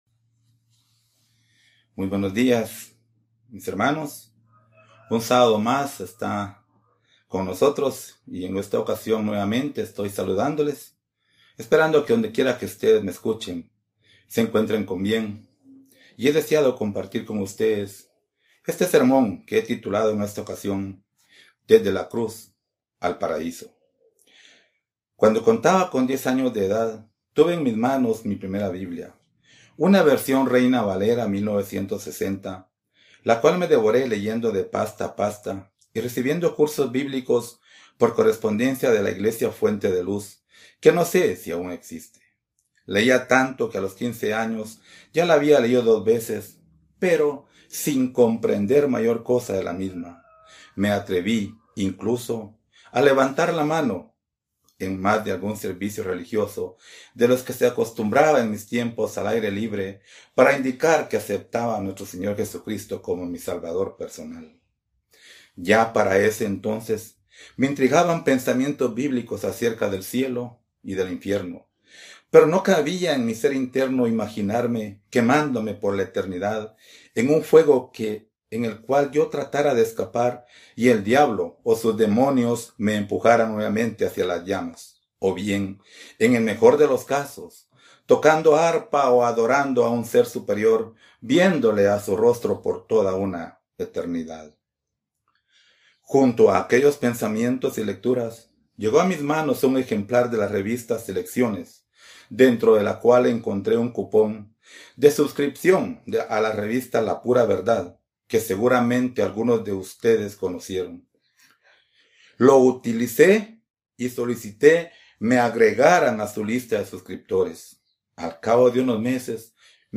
Given in Ciudad de Guatemala